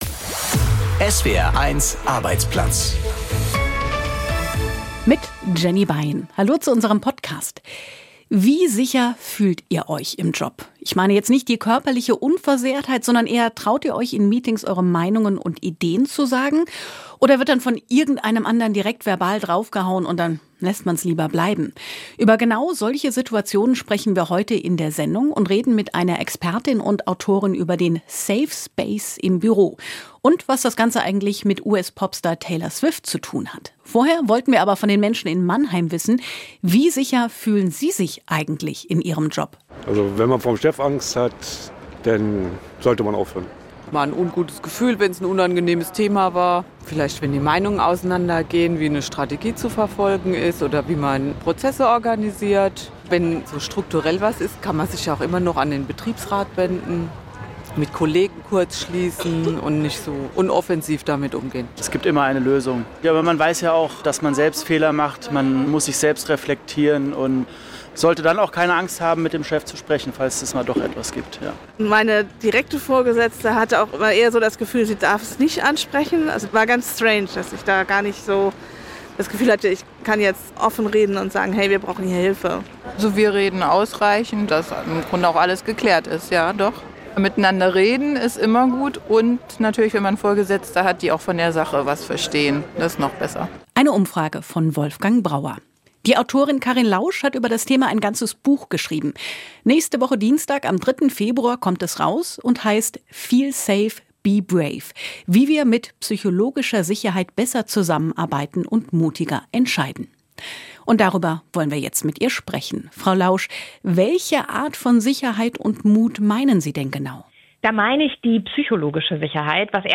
Wie sicher und unterstützt fühlen sich die Menschen in ihrem Job? Wir haben in Mannheim auf der Straße gefragt ++ „Feel safe.